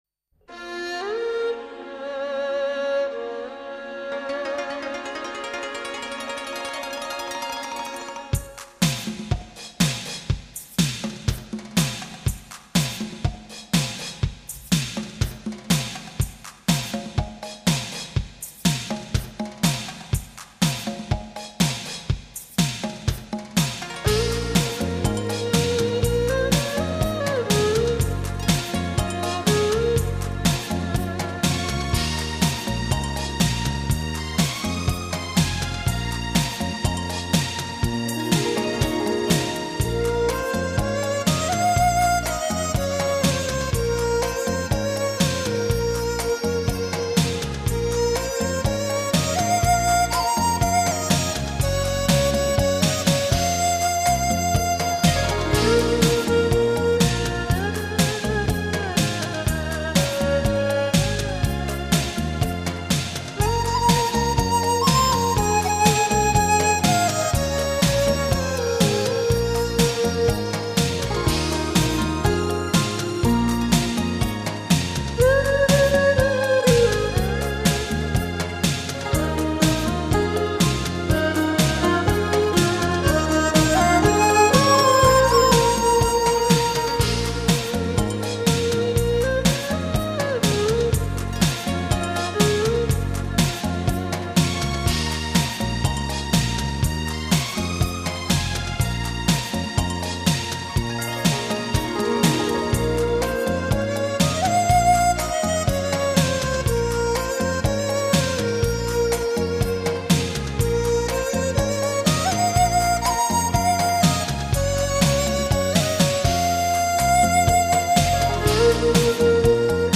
时候欣赏的民乐器轻音乐。